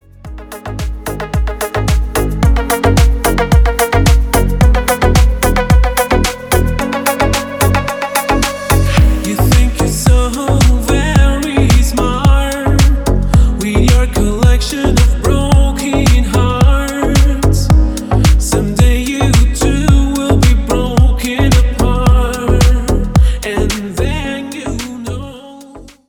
• Качество: 320, Stereo
Стиль: deep house.